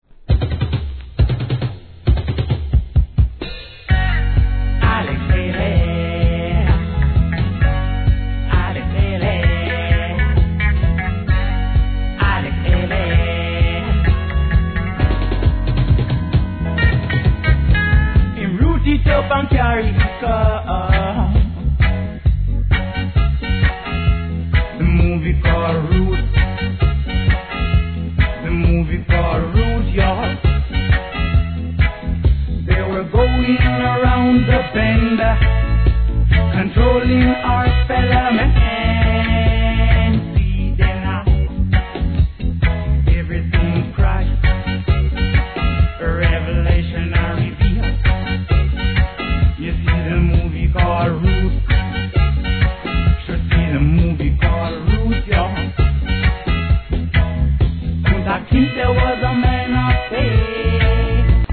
REGGAE
インディー物ROOTS、後半はDUBです。